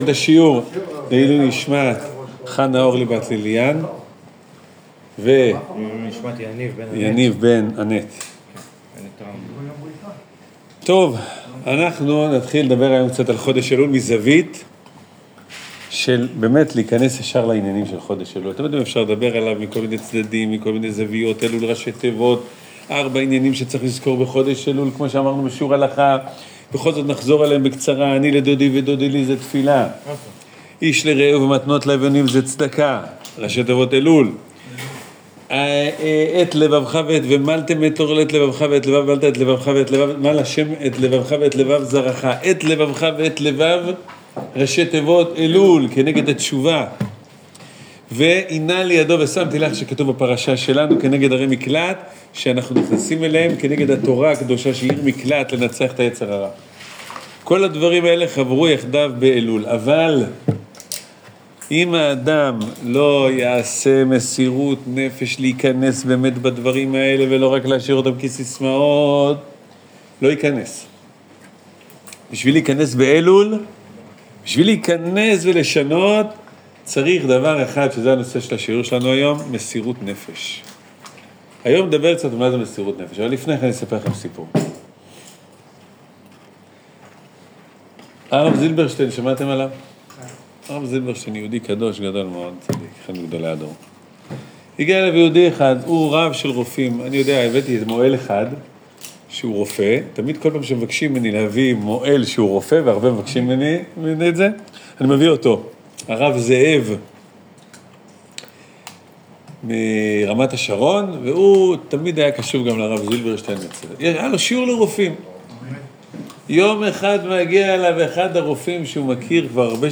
ומה קרה עם השר שהמלך לא היה מוכן בשום אופן להשאיר בחיים? שיעור מיוחד לחודש אלול. מתוך השיעור הקהילתי בבית הכנסת אחוזת הנשיא ברחובות.